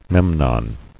[Mem·non]